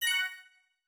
soft_win.mp3